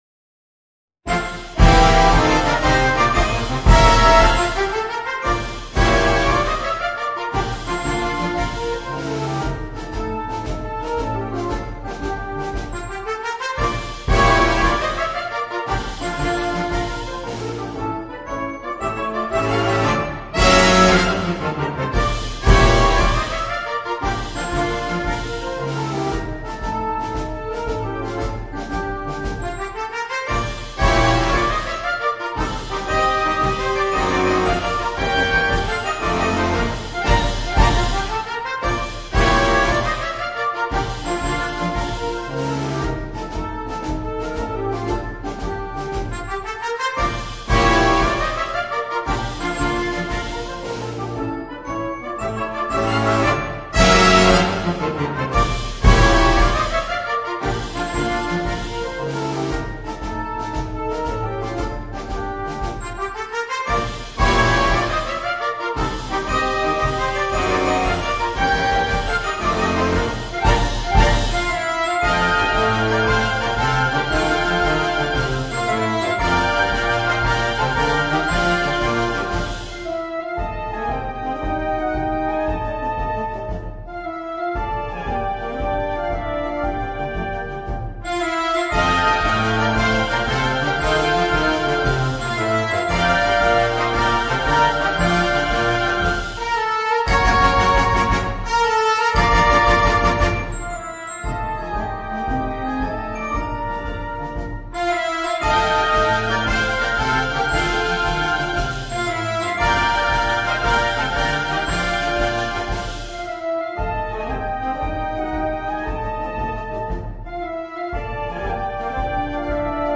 Una bella marcia da sfilata o per aprire un concerto.
MUSICA PER BANDA